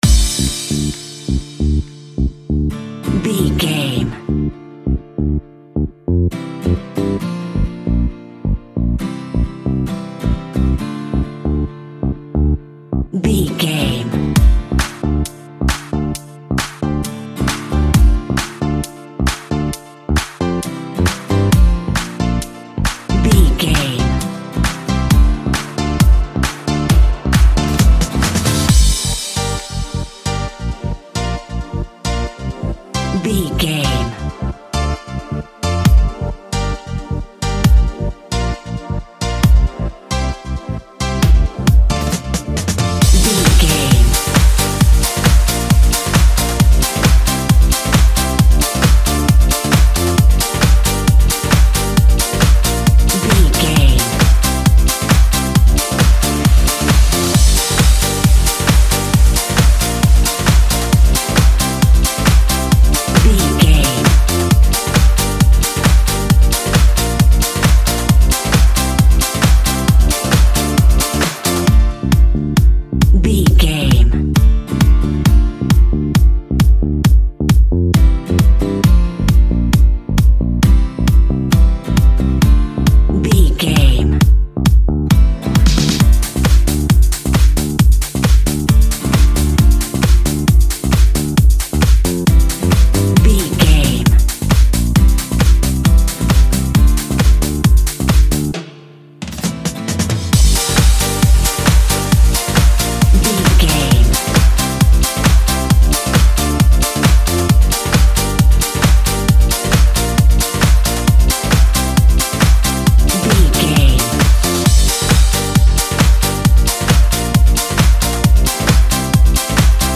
Aeolian/Minor
D
Fast
groovy
futuristic
industrial
uplifting
drum machine
synthesiser
acoustic guitar
house
electro dance
techno
trance
synth leads
synth bass
upbeat